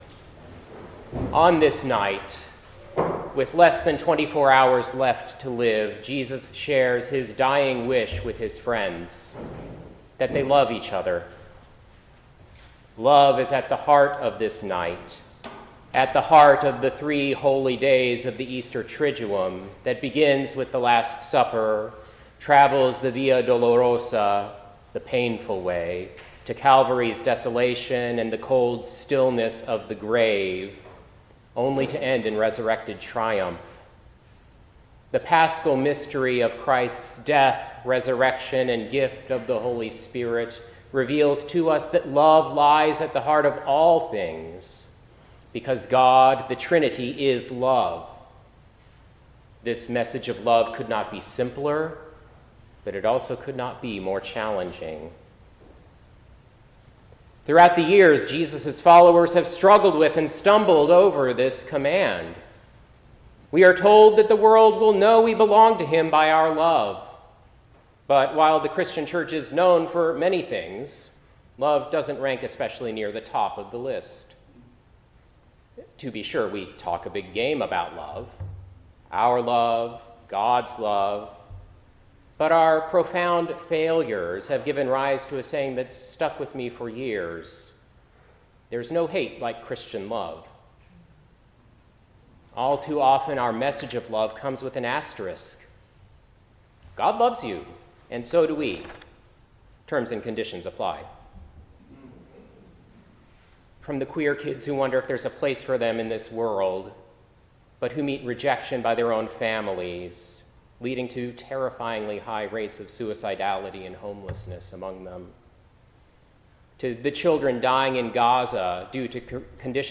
Service Type: Special Day